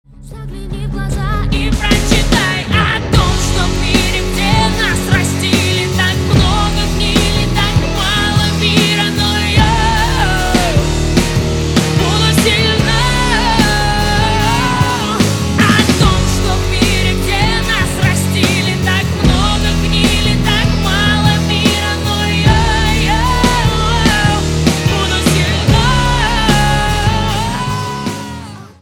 громкие
женский вокал
Rap-rock
сильные
Рэп-рок